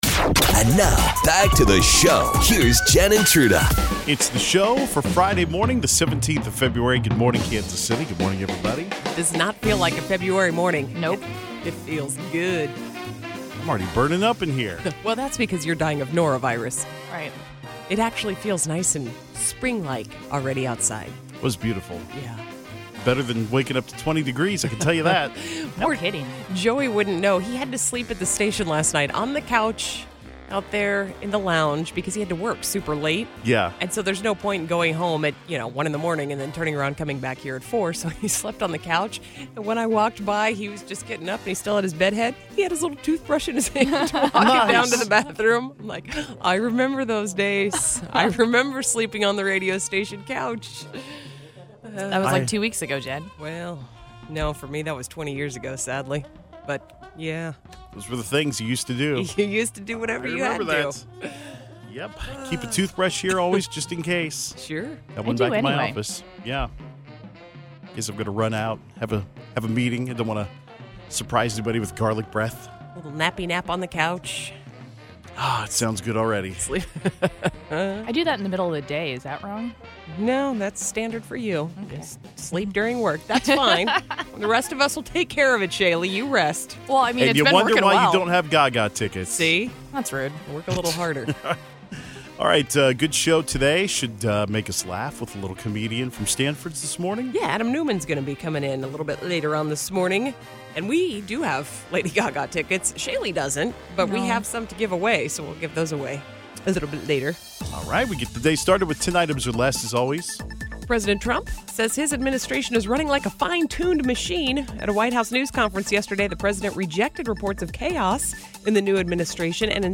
A few listeners reported the scams that have been after their paychecks.
We play The Accent Game to give a winner some Lady Gaga tickets.